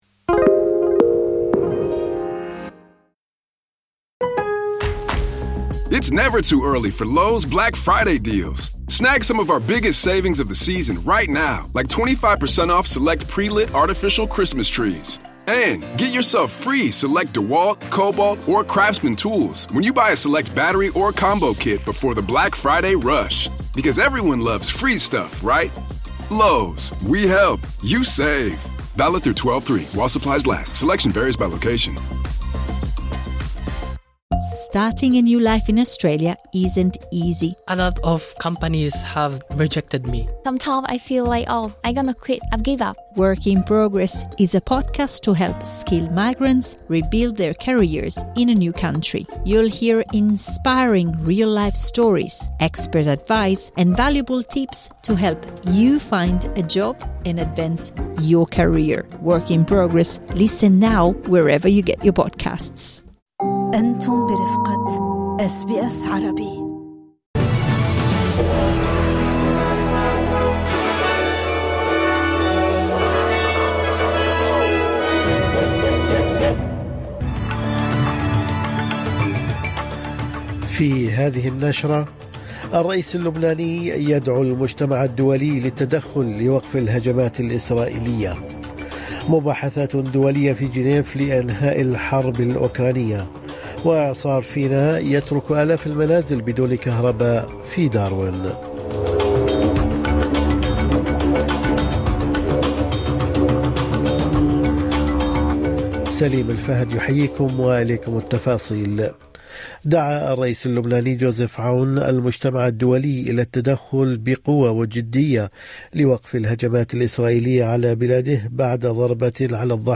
نشرة أخبار الصباح 24/11/2025